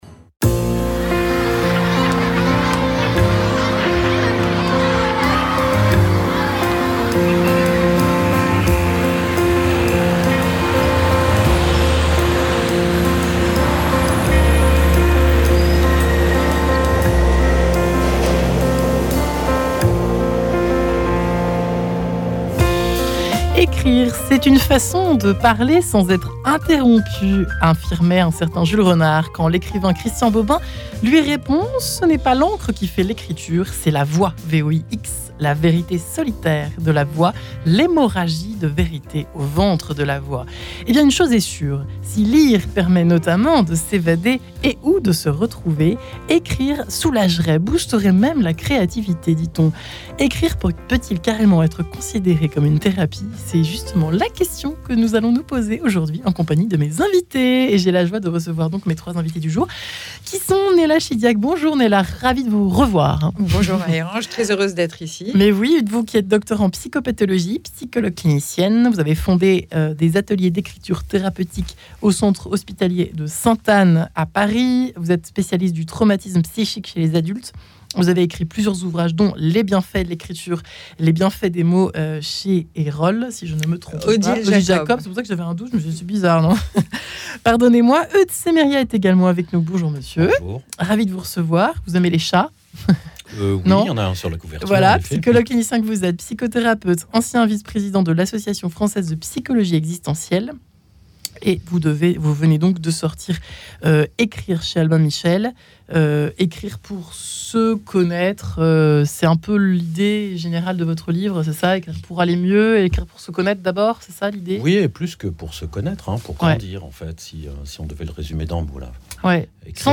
De l'hôpital St Anne, à notre atelier : Ecrire pour s'épanouir et kiffer, en passant par le rapport entre écrire et grandir, voici une discussion pas toujours d'accord qui prouve à quel point les écritures ont toutes leur style et leur mérite, jusqu'en psychiatrie.